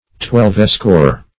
Twelvescore \Twelve"score`\, n. & a. Twelve times twenty; two hundred and forty.